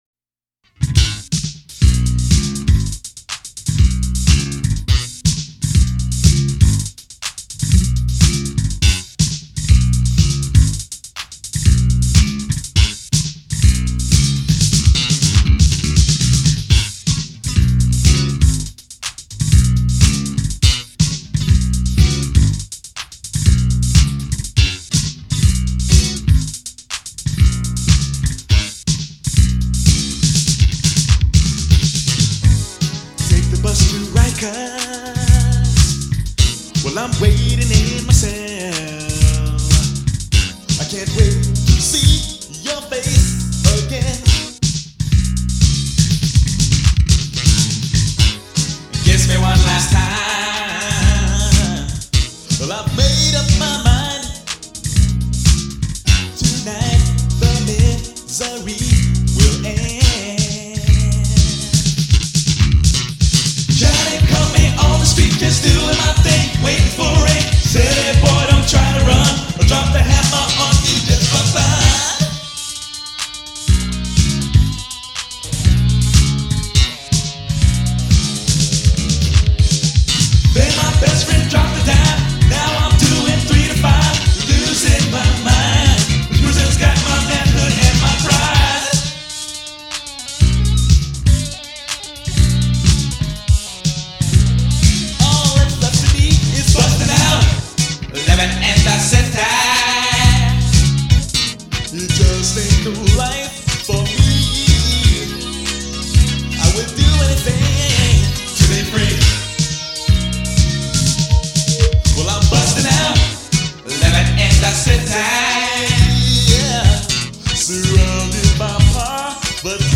Bustin' Out, also from Girls. It was still not a great vocal performance and a really bad recording
bass
Slash-style metal guitar assault